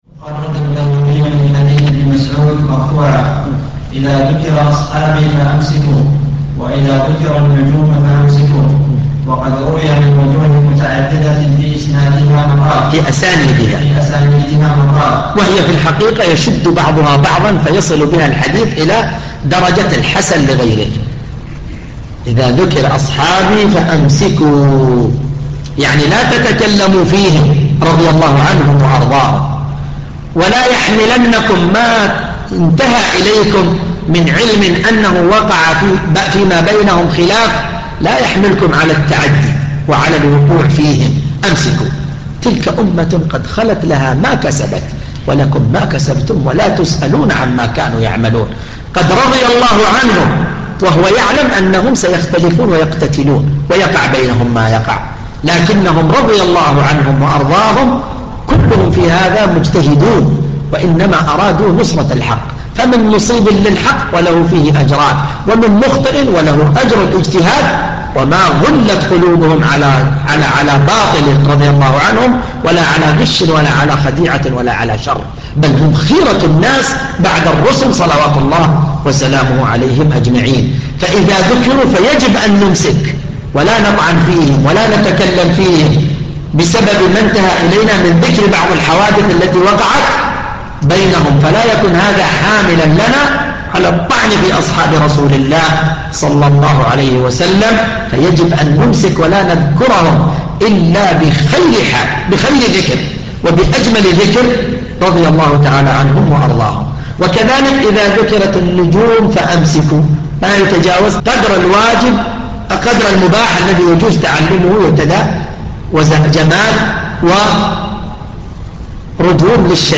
شرح حديث : (إذا ذكر ‌أصحابي فأمسكوا وإذا ذكر النجوم فأمسكوا)